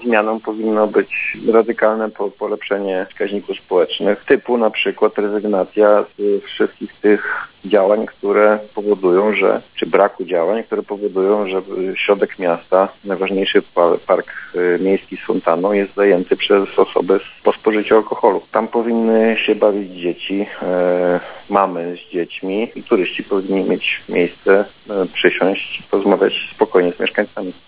O inicjatywie opowiedział Arkadiusz Nowalski, burmistrz miasta.